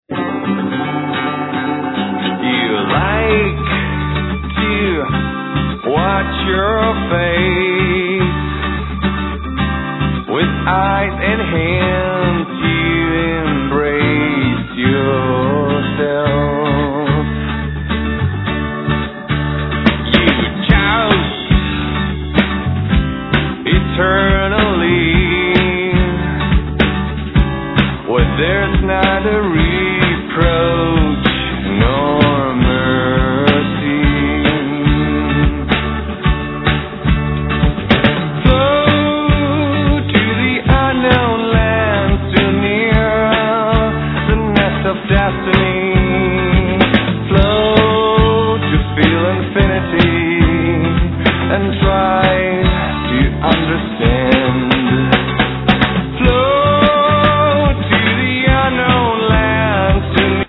El. & Ac. guitar, Back vocals.
El. guitar, Ac. & El. Violin
Bass, Double bass, Hammonds, Yamaha DX7, Percussions, Noise